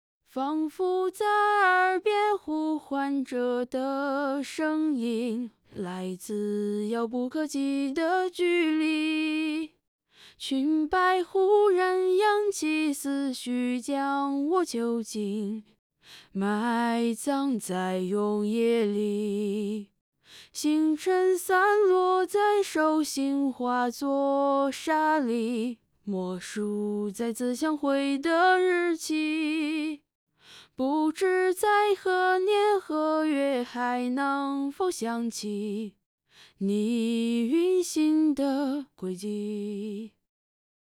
栗绪Diffsinger试听.wav